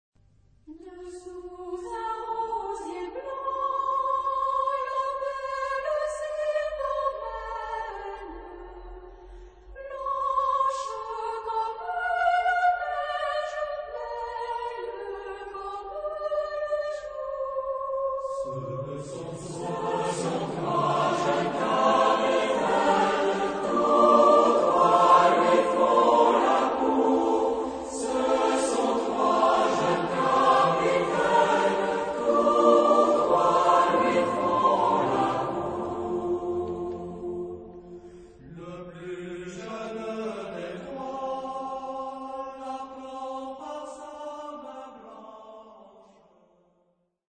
Genre-Style-Form: Popular ; Partsong ; Secular ; Lamentation
Mood of the piece: moderate
Type of Choir: SATB  (4 mixed voices )
Tonality: F major